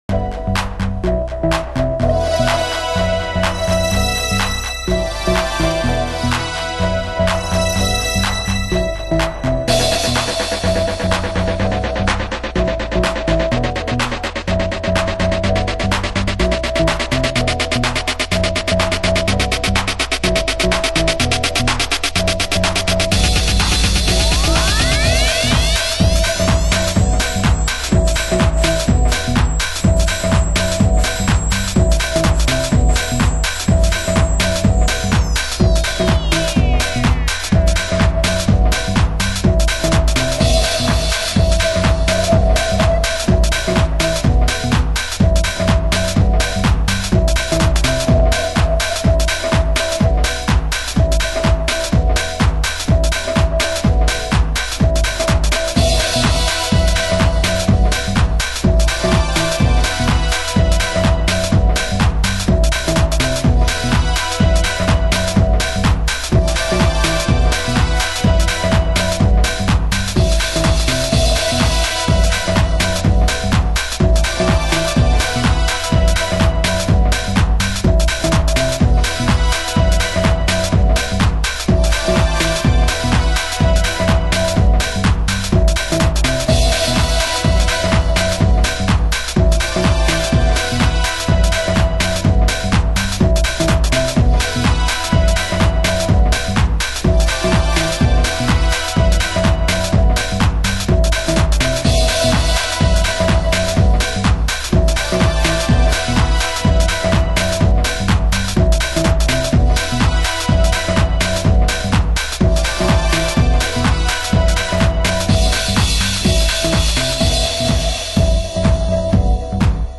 Main Vocal